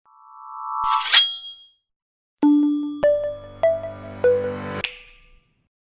Bootsound.mp3